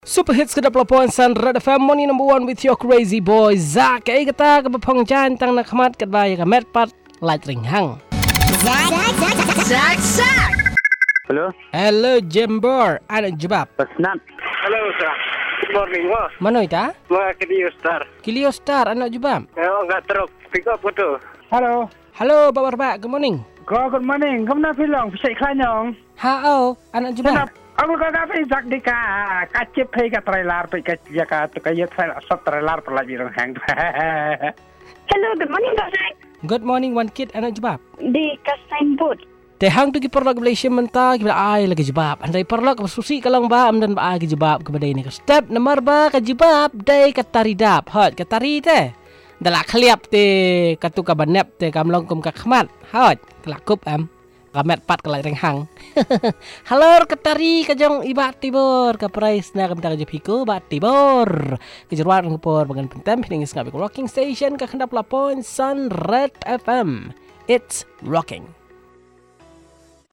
Calls and result